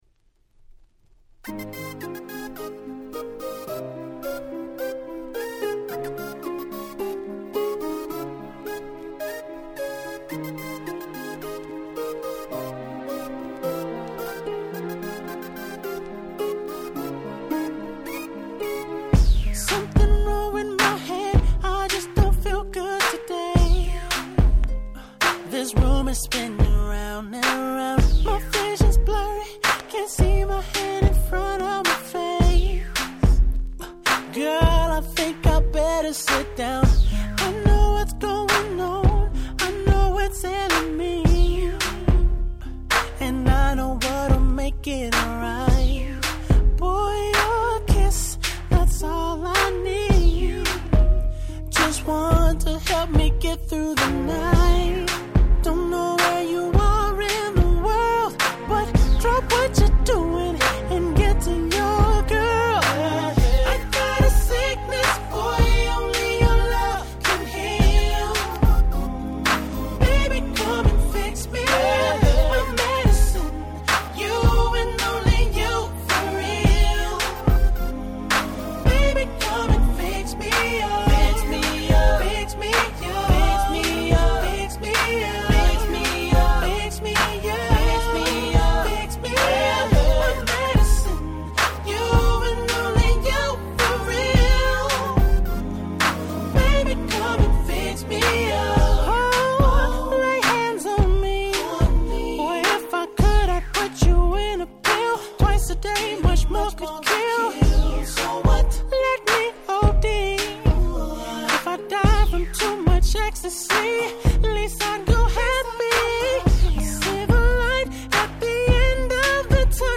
10' Nice R&B !!